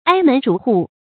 挨门逐户 āi mén zhú hù 成语解释 挨家挨户，一家也没有漏掉。